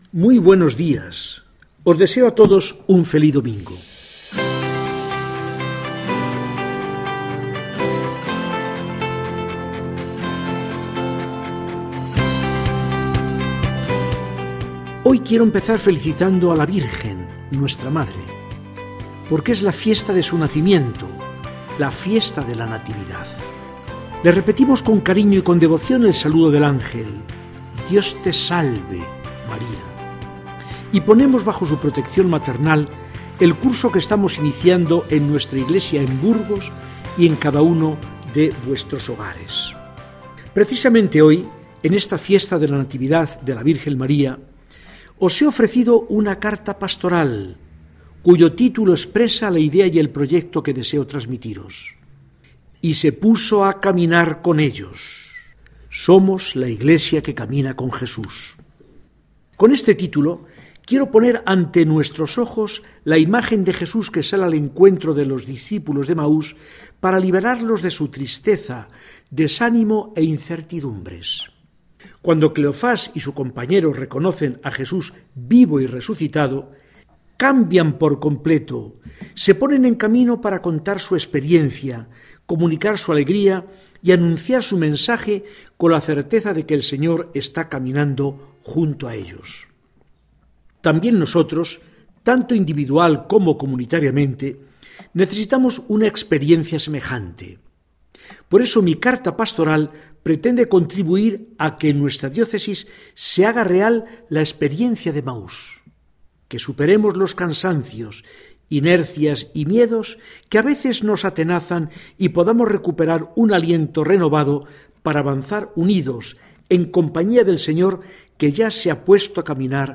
Mensaje del arzobispo de Burgos, don Fidel Herráez Vegas, para el domingo 8 de septiembre de 2019.